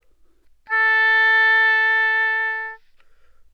Obwohl die Tonhöhe bei allen Instrumenten bei 440 Hz liegt, sind noch klare klangliche Unterschiede wahrnehmbar
Oboe_440Hz.wav